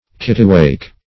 Kittiwake \Kit"ti*wake\ (k[i^]t"t[i^]*w[=a]k), n. (Zool.)